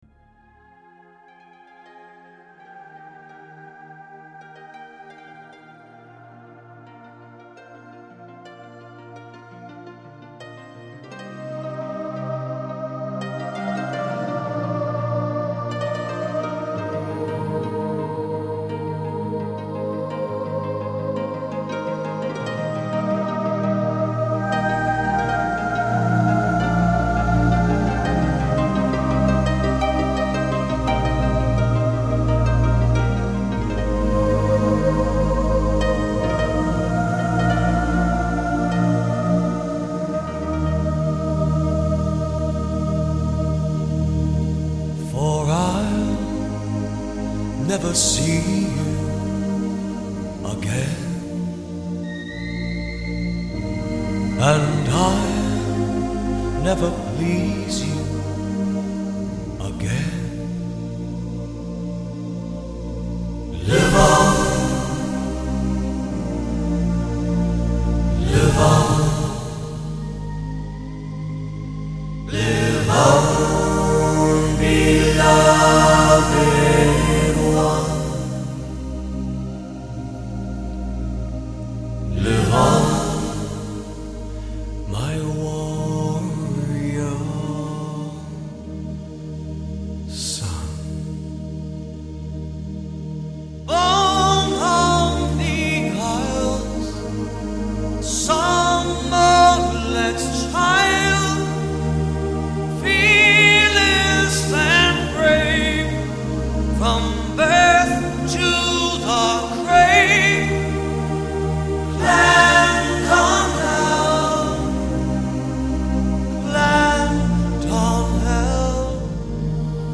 分类：Scottish Folk/Celtic